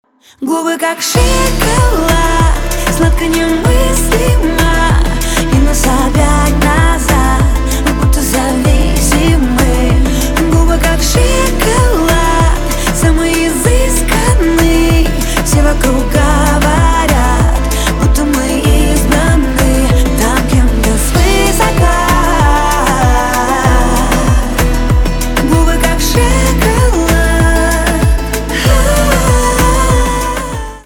поп
романтические , барабаны , гитара , чувственные